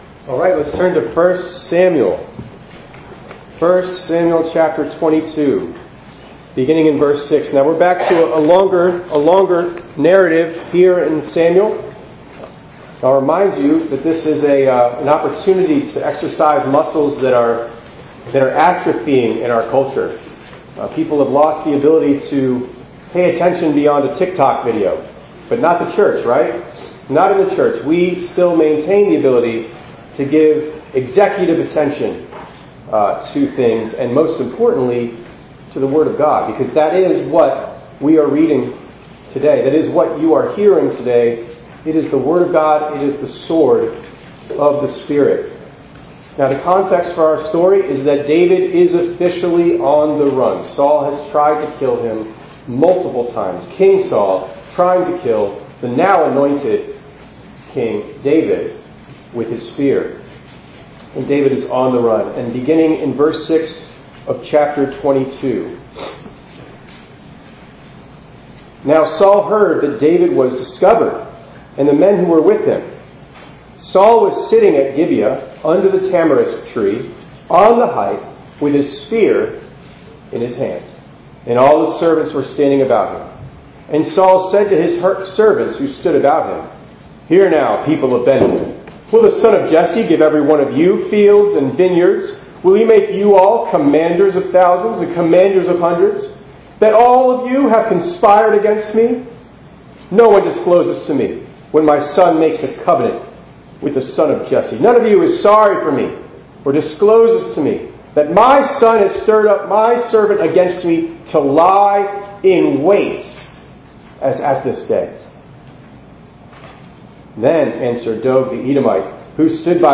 9_28_25_ENG_Sermon.mp3